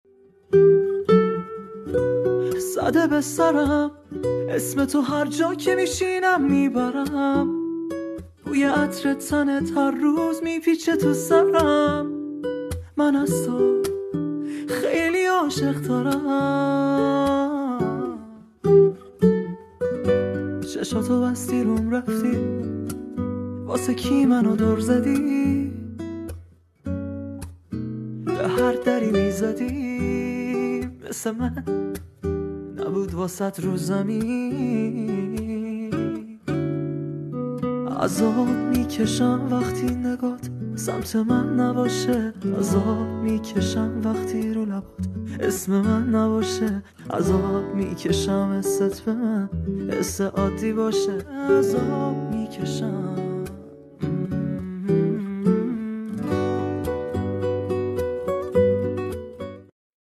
عاشقانه و غمگین